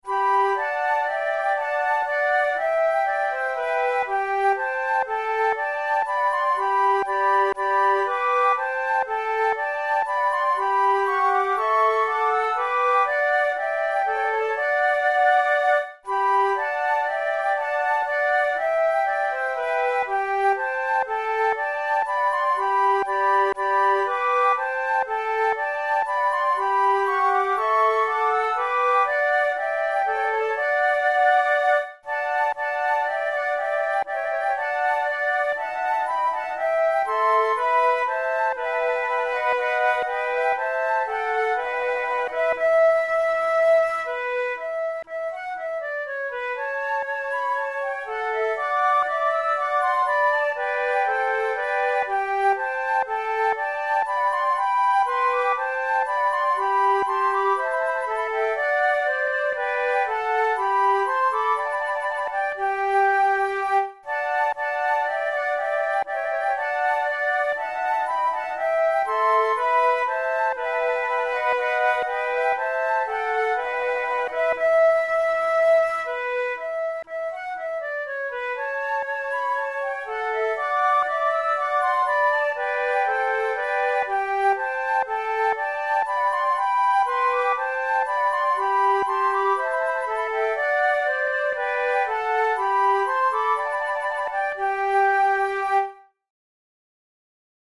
InstrumentationFlute trio
KeyG major
Time signature2/2
Tempo60 BPM
Baroque, Bourrées, Sonatas, Written for Flute